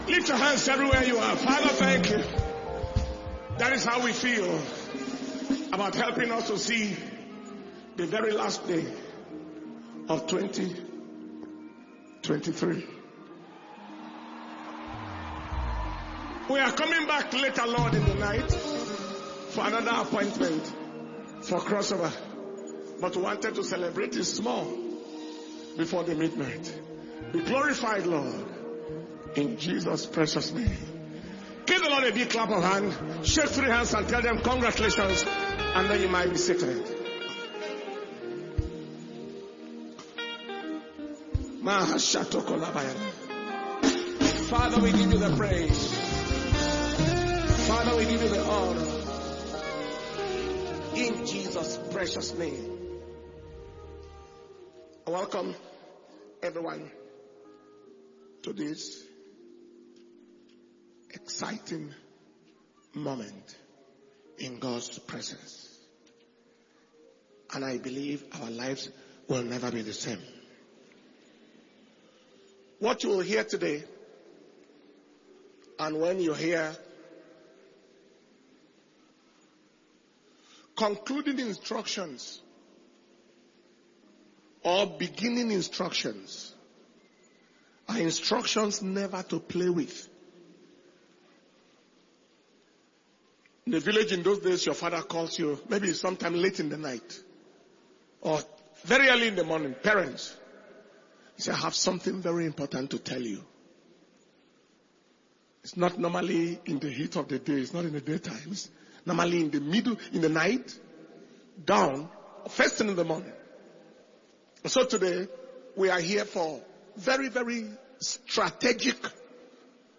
December 2023 Pre-Crossover Sunday Service – Sunday, 31st December 2023